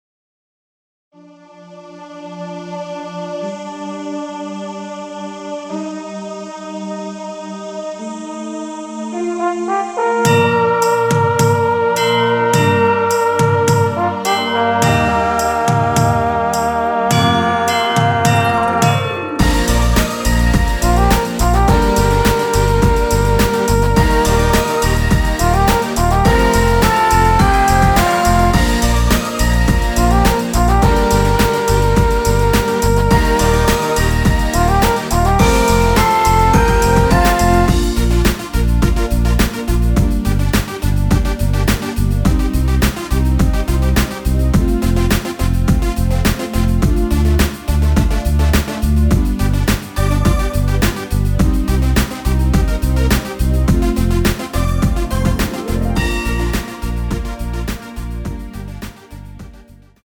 Ab
앞부분30초, 뒷부분30초씩 편집해서 올려 드리고 있습니다.